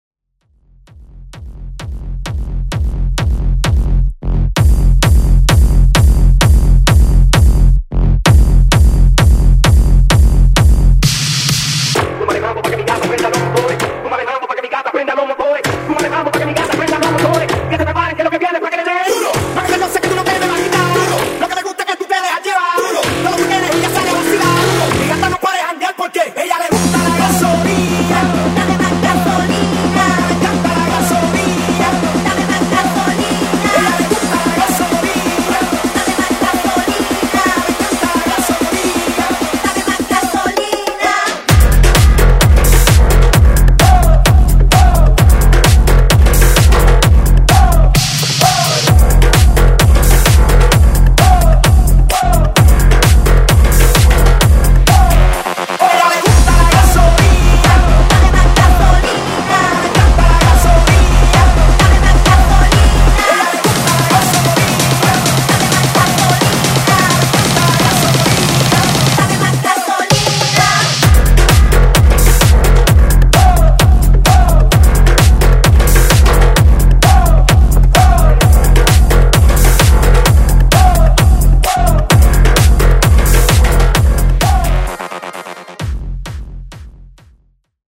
Genre: 2000's
Clean BPM: 89 Time